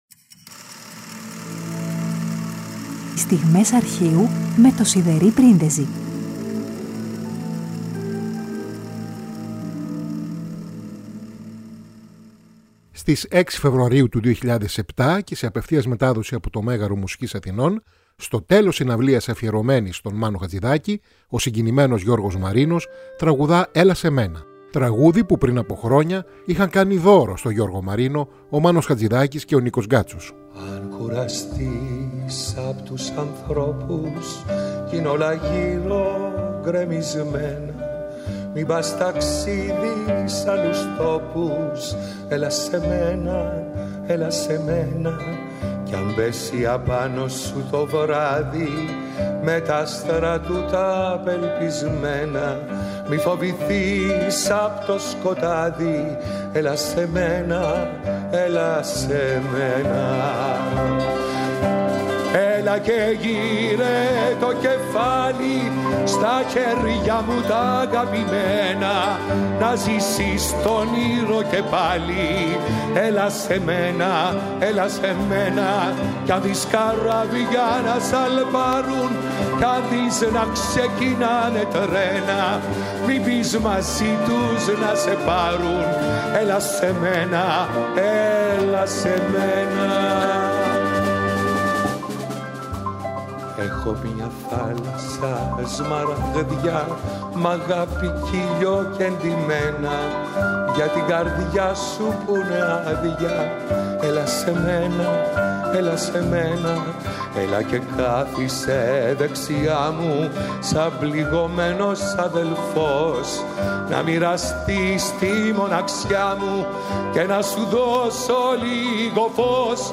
σε απευθείας μετάδοση από το Μέγαρο Μουσικής Αθηνών
στο τέλος συναυλίας